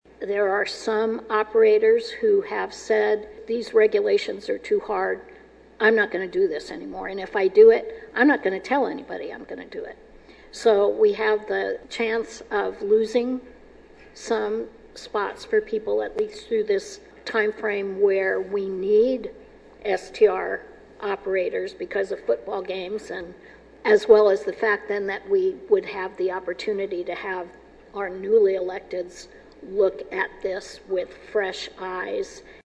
Council revisited the new rules during their meeting Tuesday night.
Councilwoman Wright asked for a moratorium on enforcement of the rules until the new city councilmembers are sworn in next year.